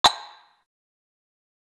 Godzilla Perc 2.wav